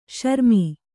♪ śarmi